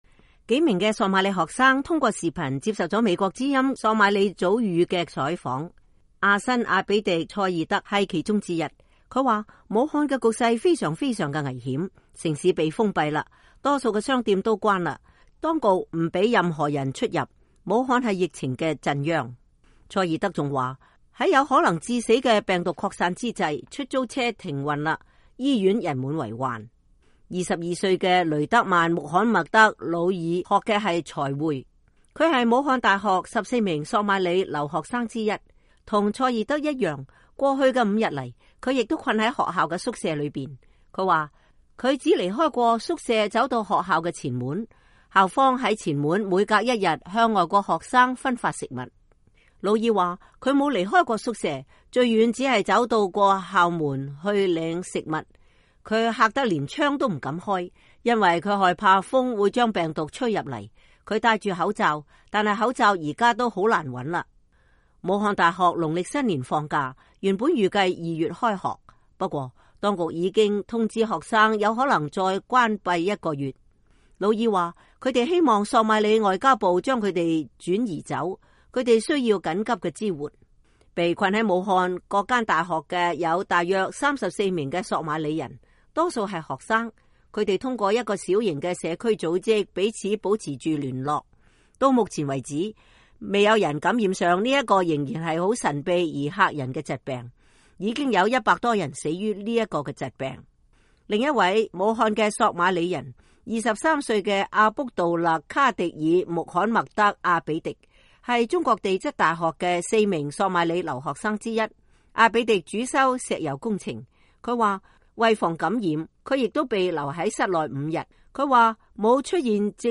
幾名索馬里學生通過Skype接受了美國之音索馬里語組的採訪。